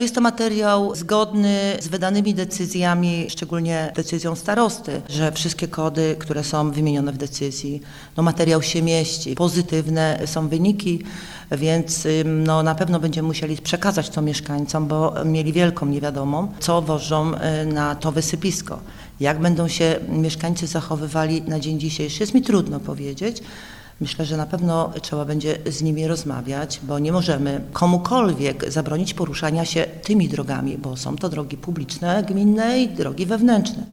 powiedziała Barbara Wróblewska, burmistrz Otynia